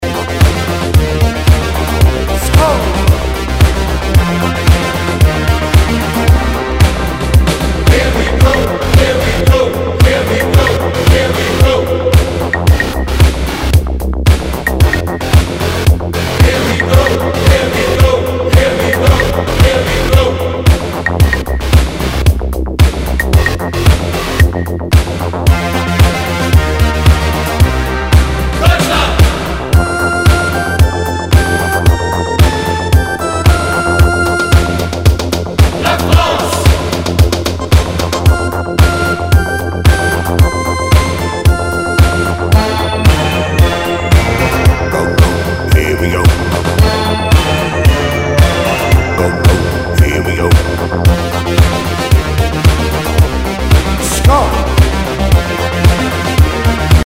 SOUL/FUNK/DISCO
ナイス！シンセ・ポップ・ディスコ！！